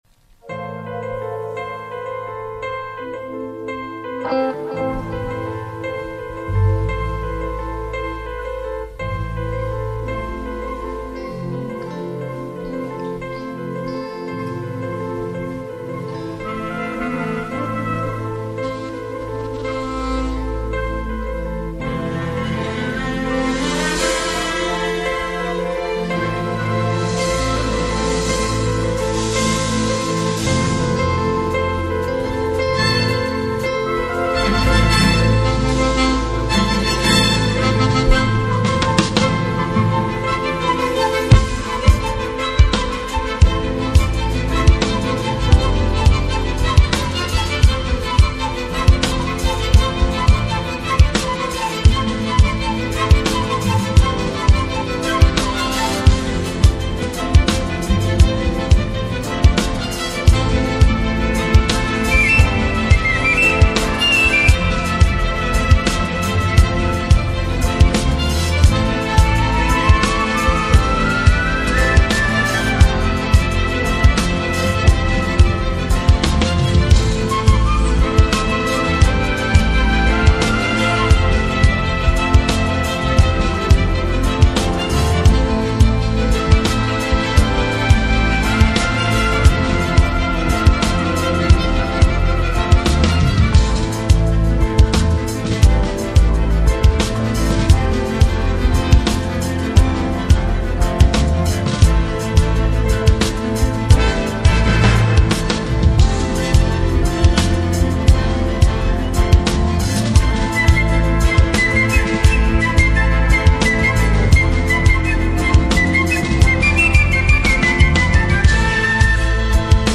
soul lifting instrumental play
MORE MUSICAL/INSTRUMENTALS